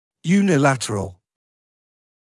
[ˌjuːnɪ’lætərəl][ˌюːни’лэтэрэл]односторонний